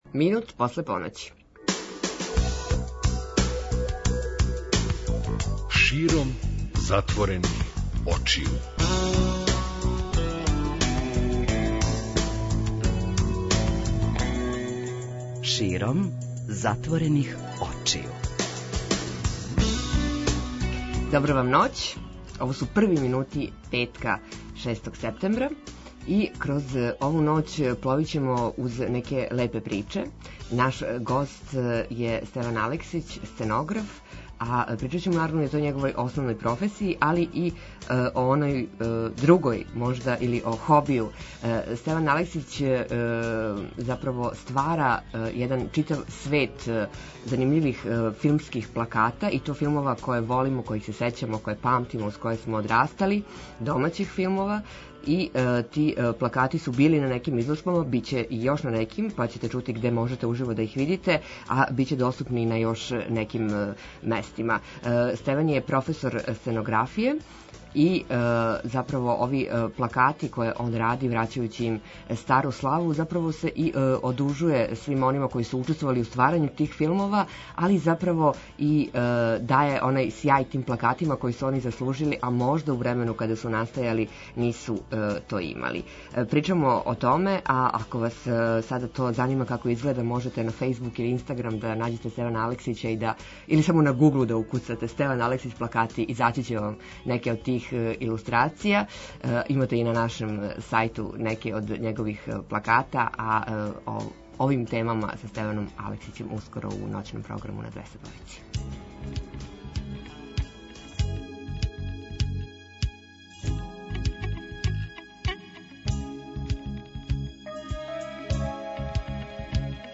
преузми : 27.09 MB Широм затворених очију Autor: Београд 202 Ноћни програм Београда 202 [ детаљније ] Све епизоде серијала Београд 202 Устанак Устанак Брза трака Брза трака: Млади у саобраћају Како сте спавали?